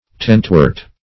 Search Result for " tentwort" : The Collaborative International Dictionary of English v.0.48: Tentwort \Tent"wort`\, n. (Bot.) A kind of small fern, the wall rue.